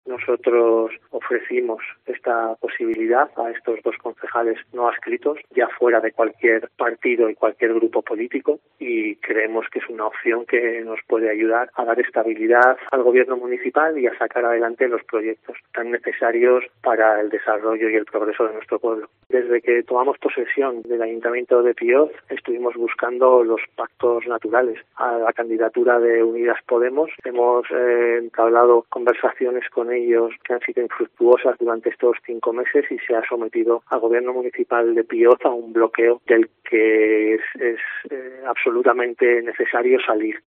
Pues bien, hoy, en Mediodía COPE en Guadalajara, el alcalde piocero, Manuel López Carvajal, ha defendido el acuerdo con ambos ediles como la "opción que nos puede ayudar a dar estabilidad al Gobierno municipal y a sacar adelante los proyectos tan necesarios para el desarrollo y el progreso de nuestro pueblo", dejando muy claro que se ha hecho posible porque se trata de "dos concejales no adscritos, ya fuera de cualquier partido y de cualquier grupo político".